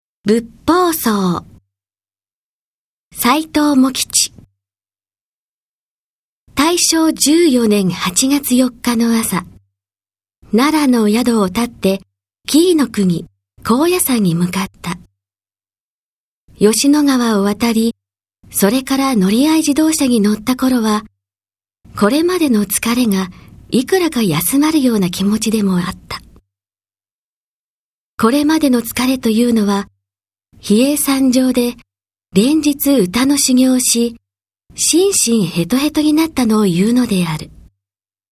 朗読ＣＤ　朗読街道134「仏法僧鳥・最上川・曼珠沙華・遍路・支流」
朗読街道は作品の価値を損なうことなくノーカットで朗読しています。